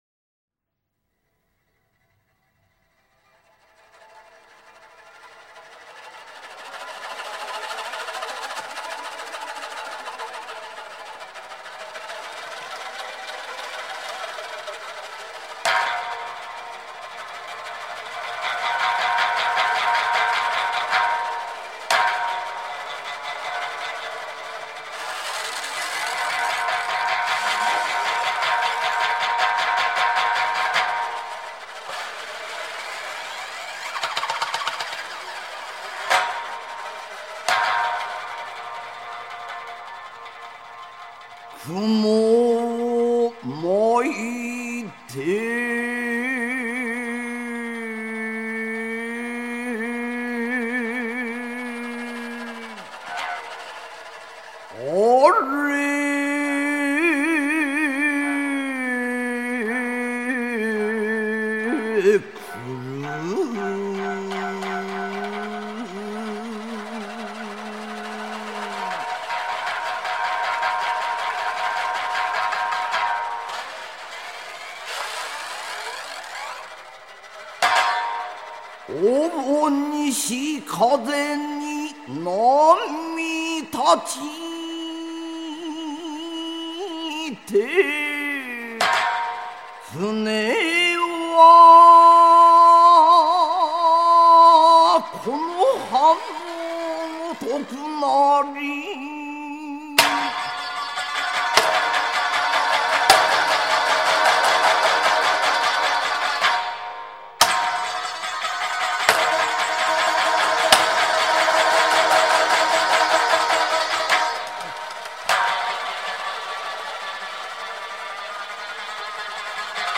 剧力万钧的琵琶剧唱电击您的耳朵